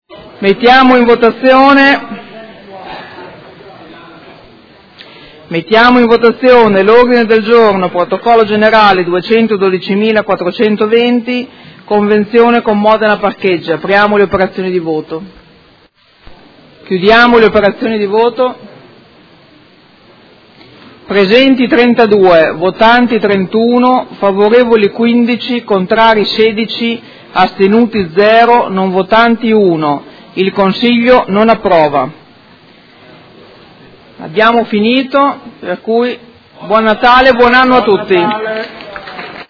Seduta del 20/12/2018. Mette ai voti Ordine del Giorno Prot. Gen. 212420. Chiusura lavori